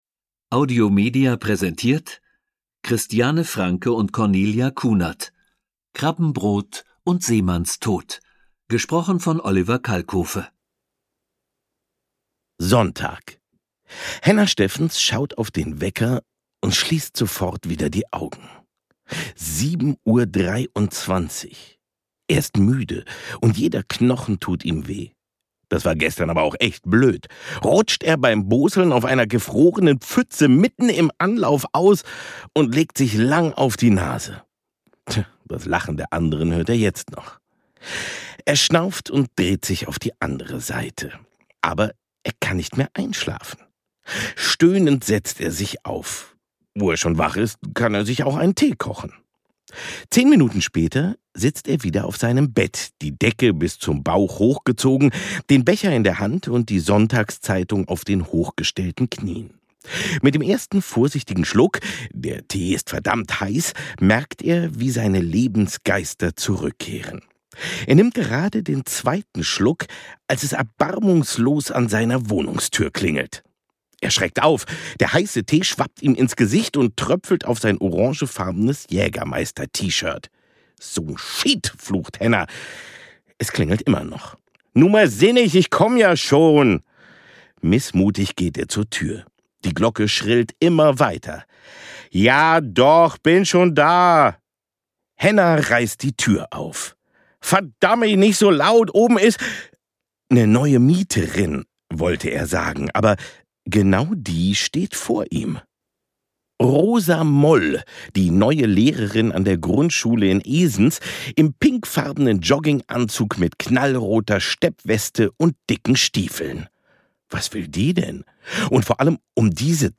Ein Ostfriesenkrimi
Oliver Kalkofe (Sprecher)